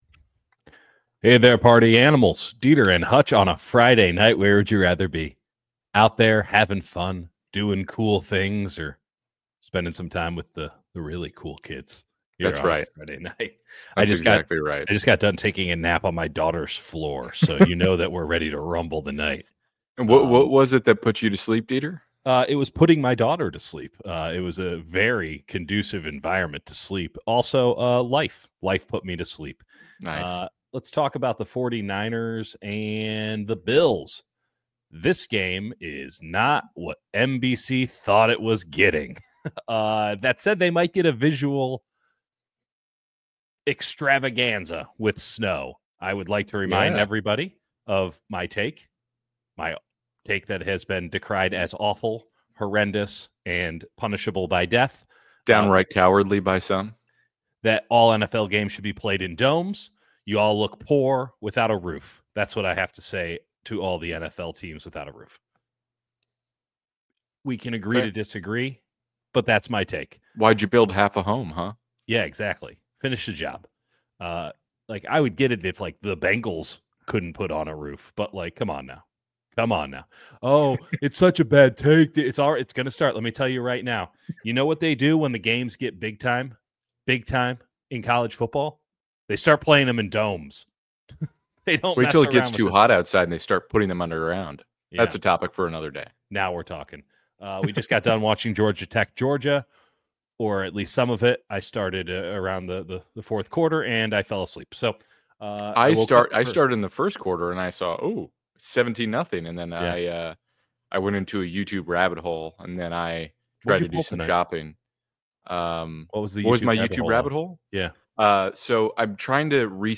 Does Brock Purdy Give the 49ers A Chance in Buffalo? | LIVE